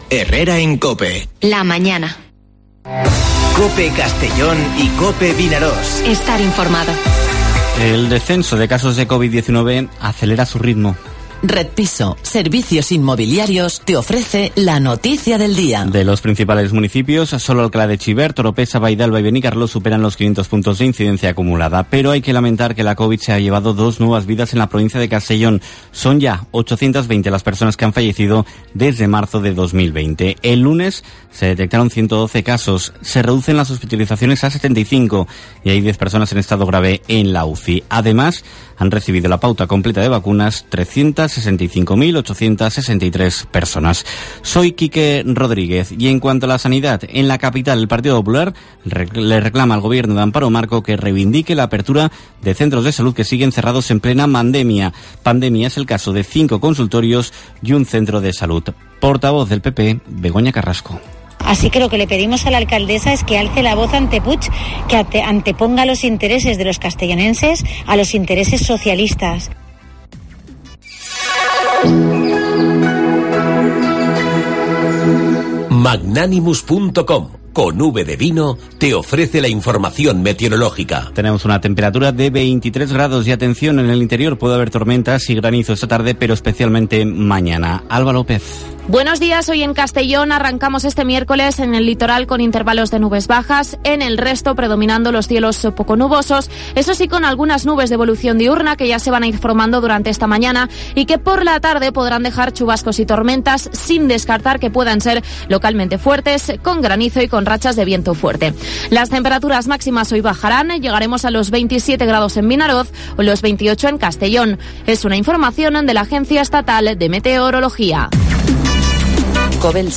Informativo Herrera en COPE en la provincia de Castellón (18/08/2021)